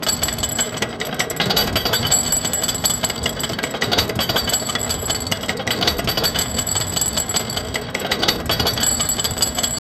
looping-sound-of-a-huge-p25nqe37.wav